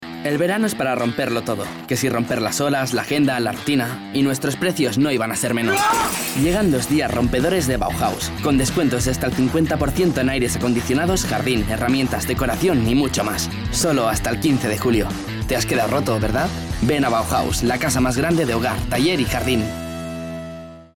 sehr variabel
Jung (18-30)
Eigene Sprecherkabine
Commercial (Werbung), Narrative, Off